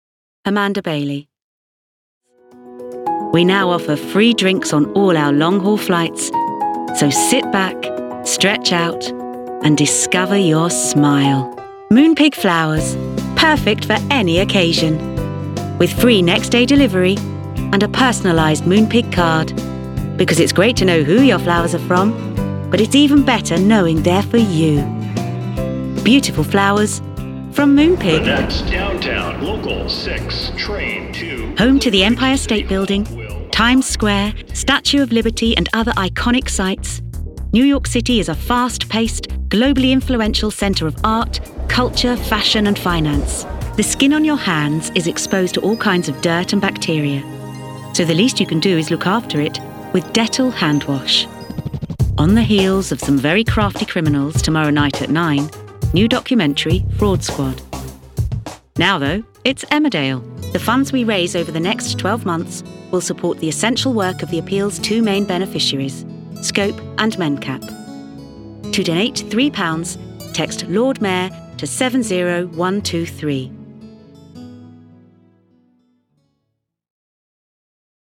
Positive, Bright and Upbeat